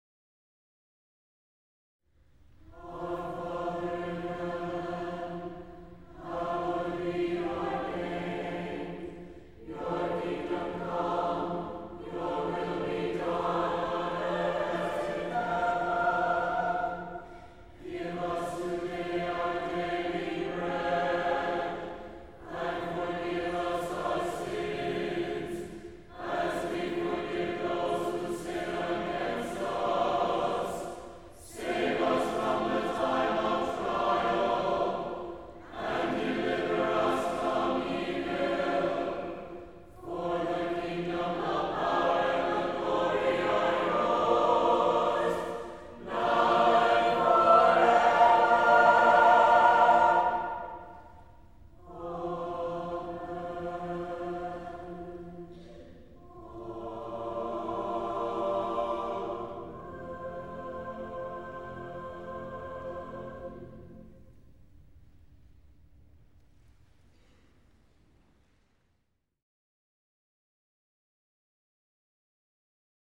Harmonized Chant Setting SATB divisi